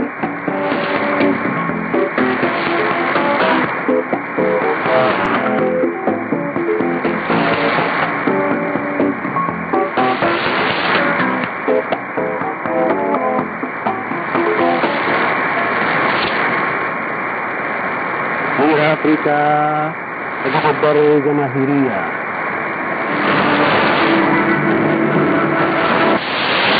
ID: identification announcement